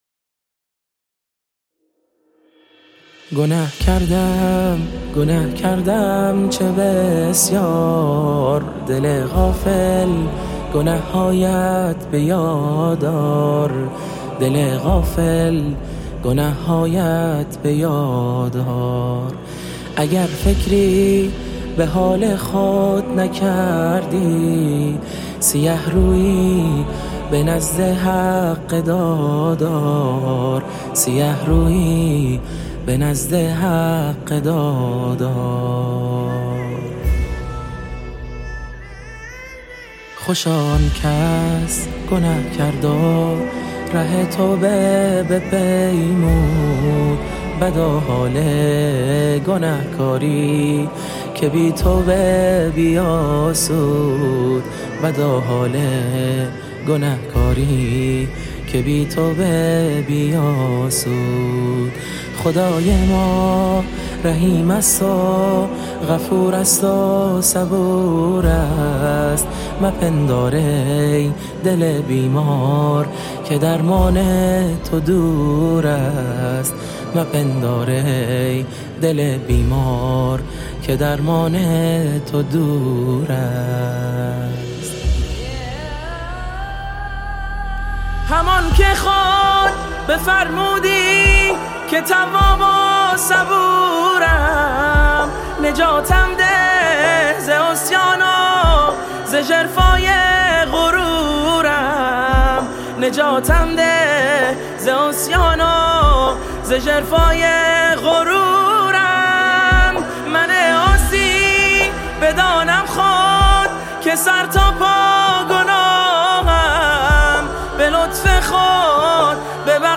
نغمه‌ایست
خوانندۀ نام‌آشنا با لحنی دلنشین ارائه شده است